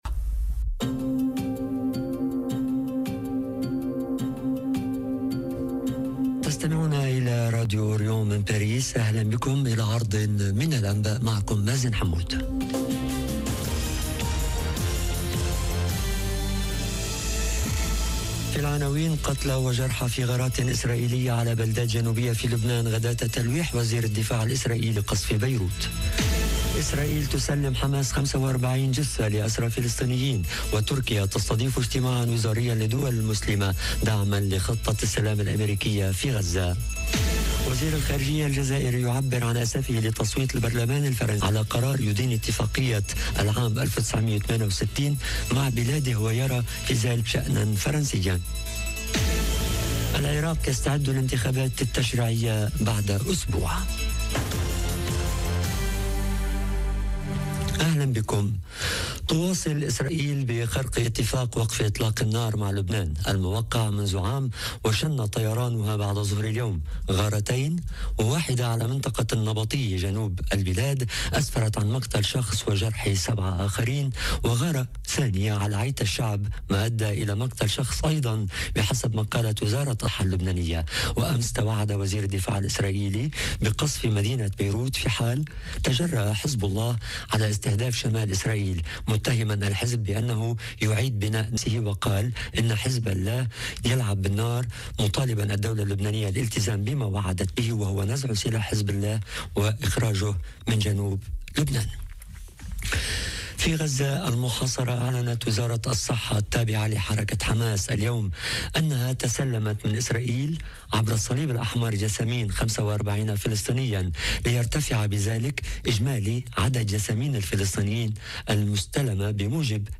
نشرة أخبار المساء: قتلى وجرحى في غارات إسرائيلية على بلدات جنوبية في لبنان غداة تلويح وزير الدفاع الإسرائيلي بقصف بيروت - Radio ORIENT، إذاعة الشرق من باريس